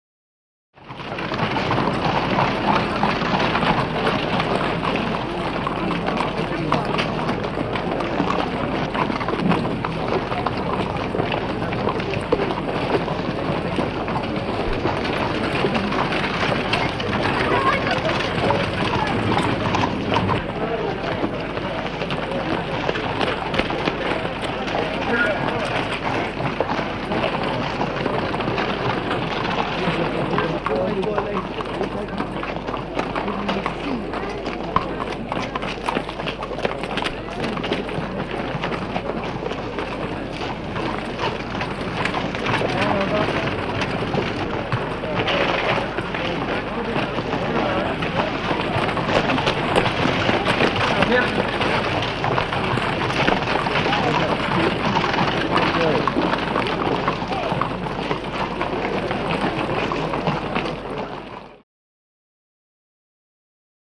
Medieval Town BG | Sneak On The Lot
Horses; Period Town Atmosphere; Period Town Atmosphere. People, Horses, Carriages Etc.